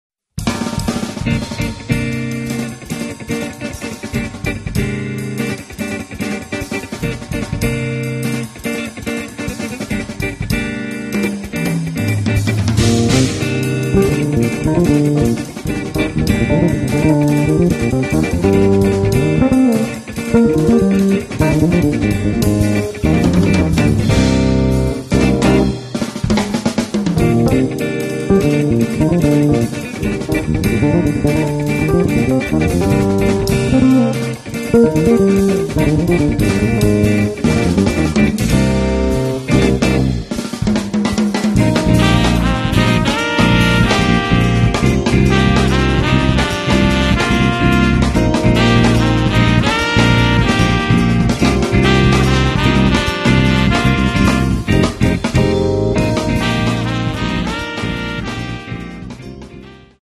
Registrato presso lo “Orange Bug” studio di Napoli